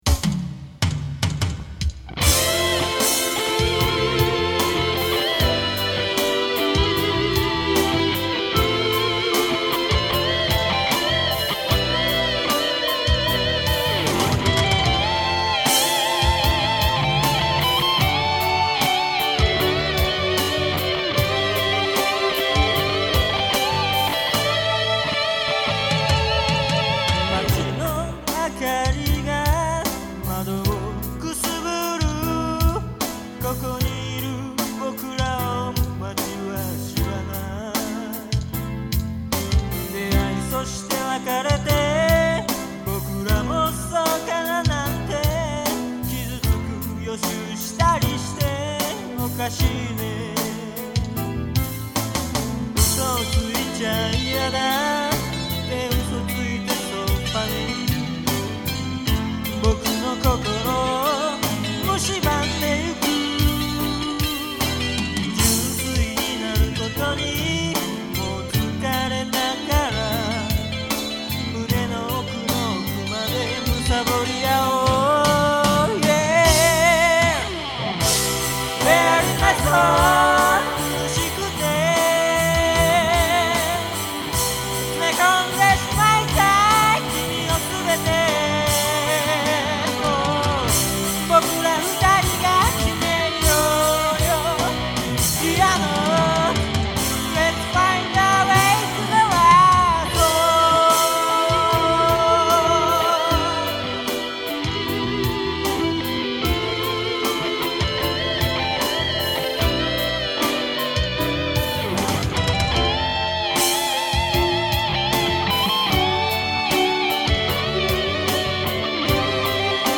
Vocal
Guitar
Piano
Chorus